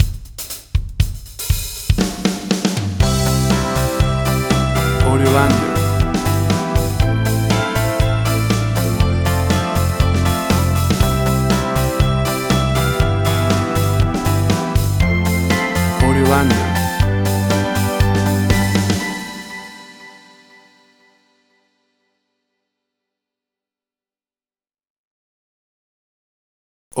música optimista ideal para show de tv
Tempo (BPM): 120